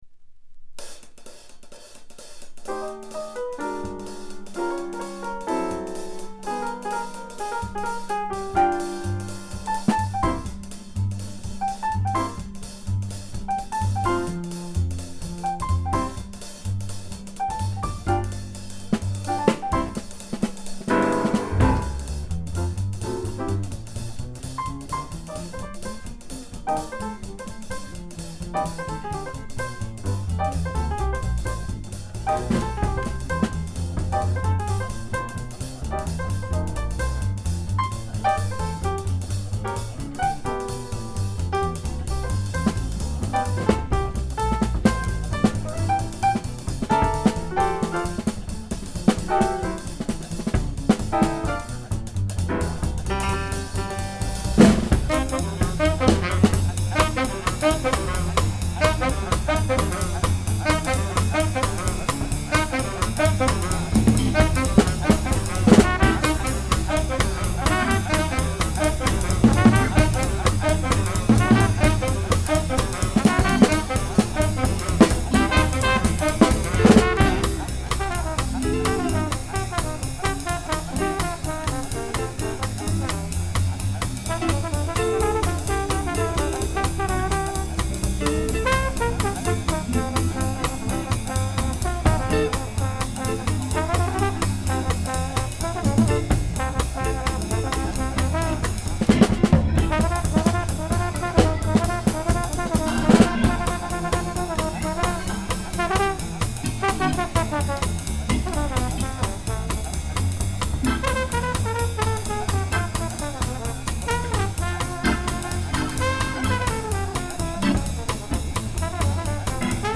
比較試聴した時の楽曲は全てDSD 5.6M で録音しました。
ジャズ